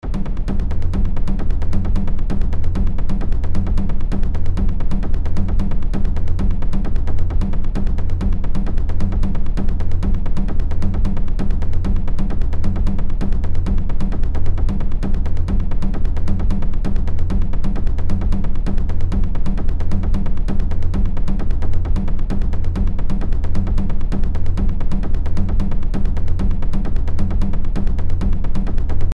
テンポのいい太鼓の音。